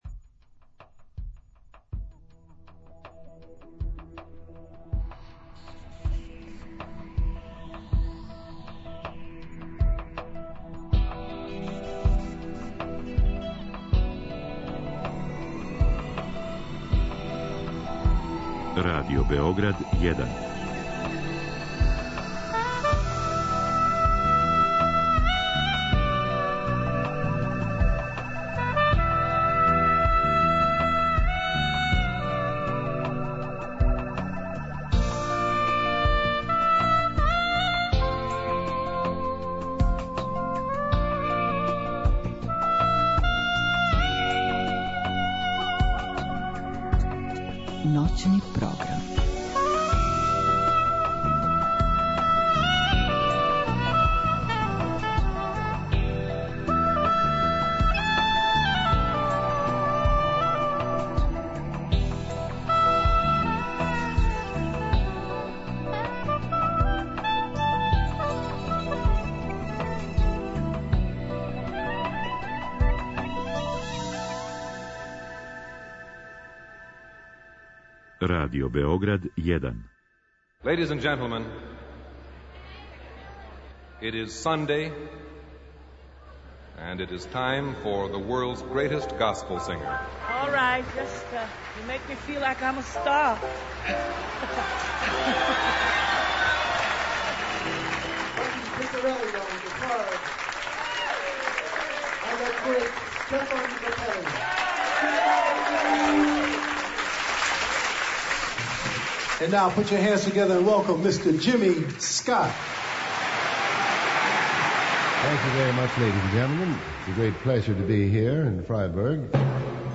И током сва цетири сата емитовацемо концертне снимке...